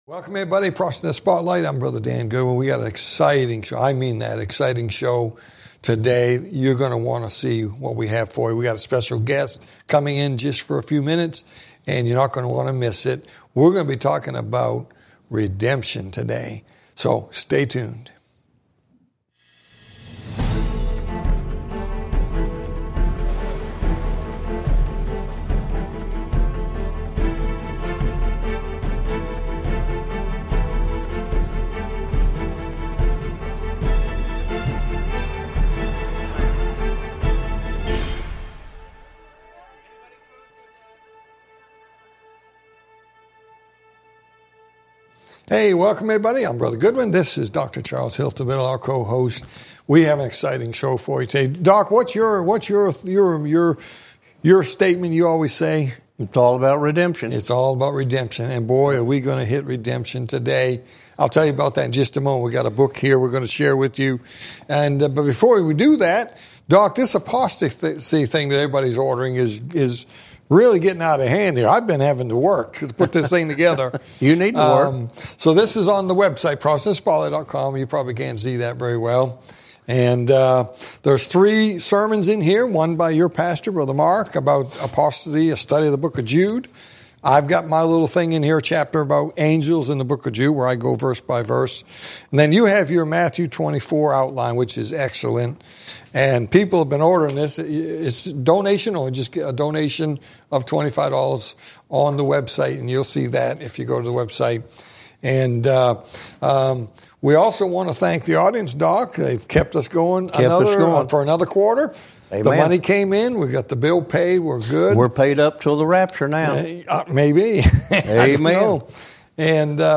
Talk Show Episode, Audio Podcast, Prophecy in the Spotlight and Getting Loved Ones Saved in These Last Days - Ep 193 on , show guests , about Getting Loved Ones Saved in These Last Days, categorized as Health & Lifestyle,History,Love & Relationships,Philosophy,Psychology,Christianity,Inspirational,Motivational,Society and Culture